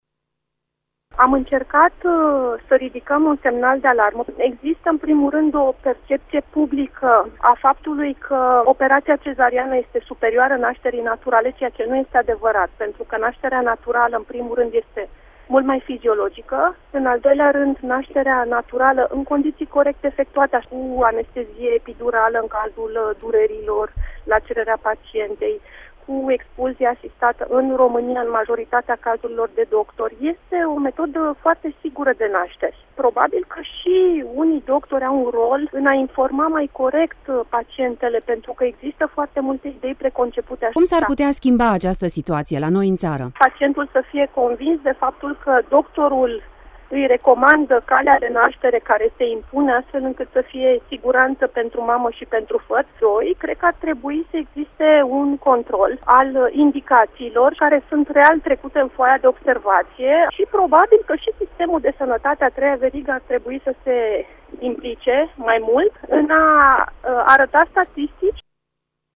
a stat de vorbă la emisiunea Pulsul zilei de la RTM